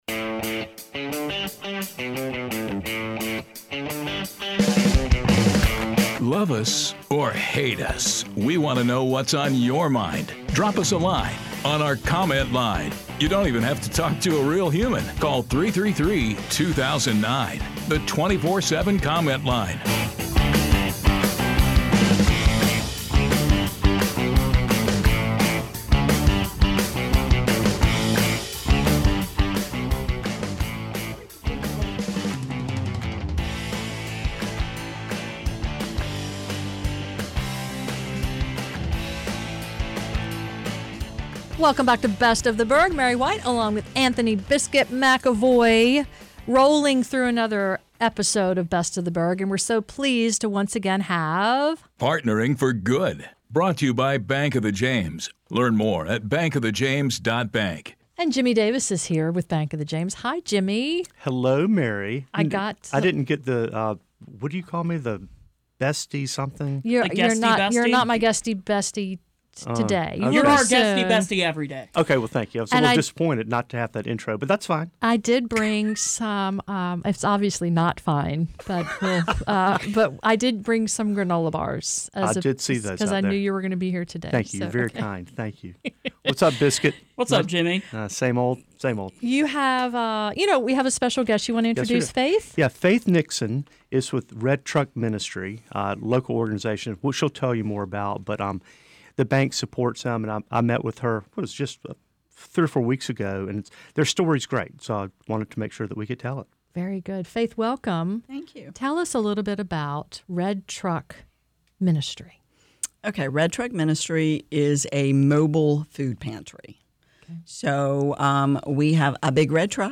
Red Truck Ministry on the Radio!